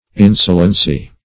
insolency - definition of insolency - synonyms, pronunciation, spelling from Free Dictionary Search Result for " insolency" : The Collaborative International Dictionary of English v.0.48: Insolency \In"so*len*cy\, n. Insolence.